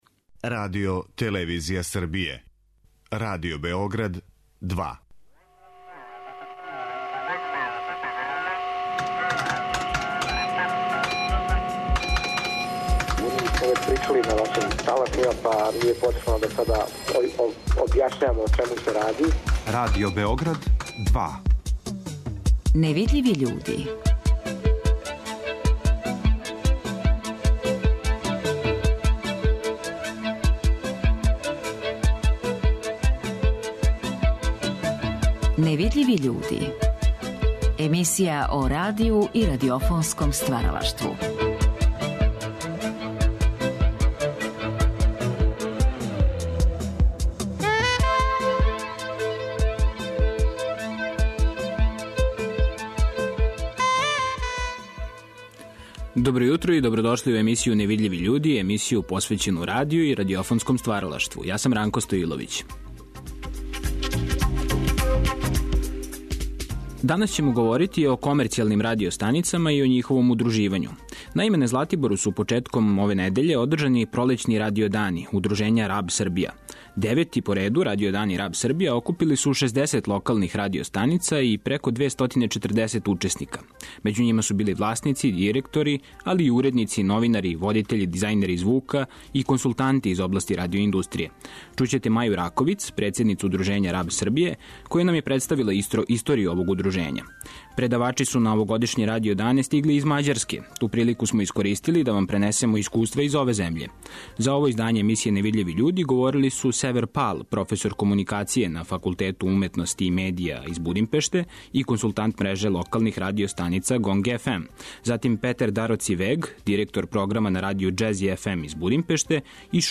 На Златибору су одржани Пролећни Радио дани Удружења РАБ Србија.